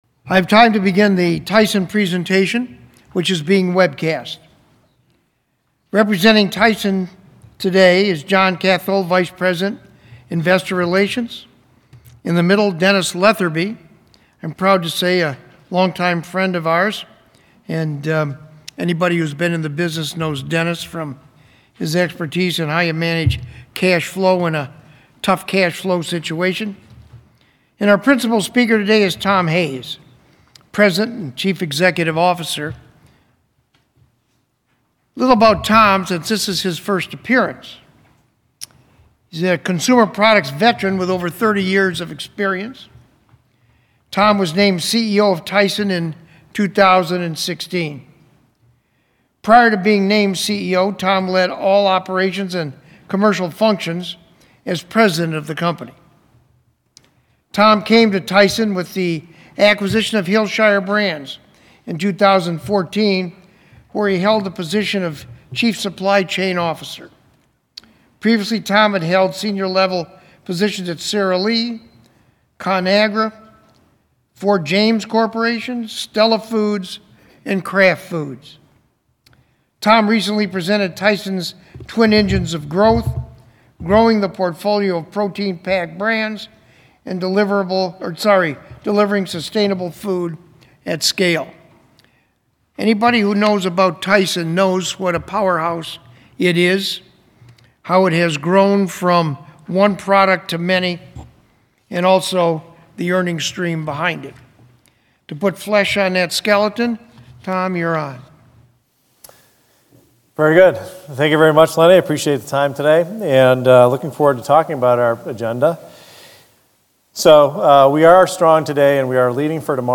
Tyson Foods Inc. - Tyson Presentation at Consumer Analyst Group of Europe